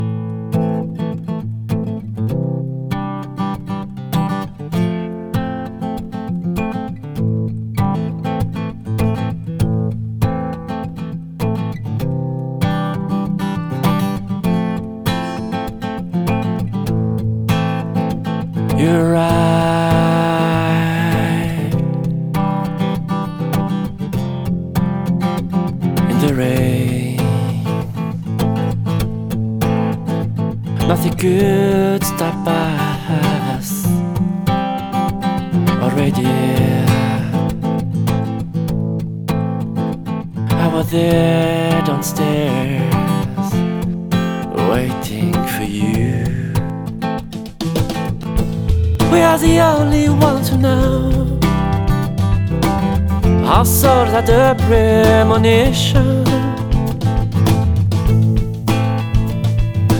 Entre folk intimiste, accents soul et éclats rock
Guitariste passionné